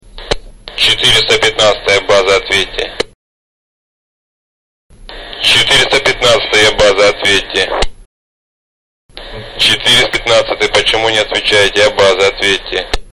Вас вызывает по рации база.